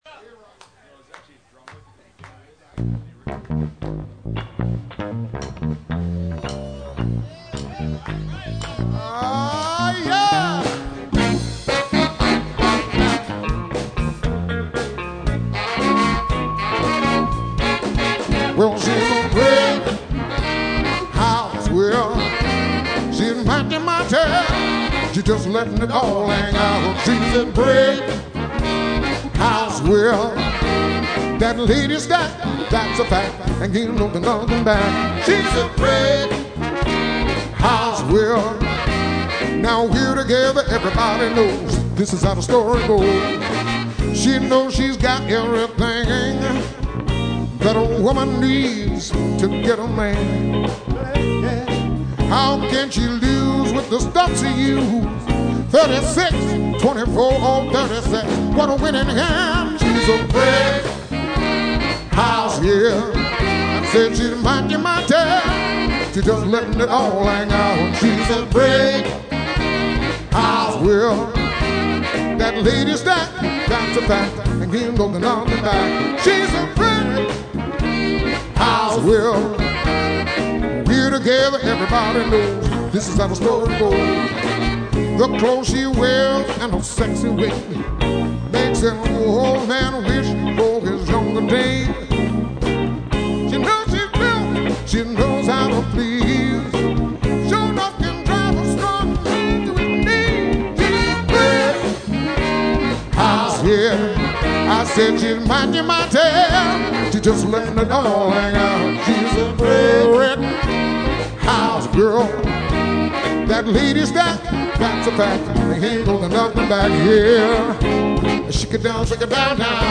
R&B, Soul, and Funk<
Roger Sherman Baldwin Park Greenwich, CT
( 2 trk live recording)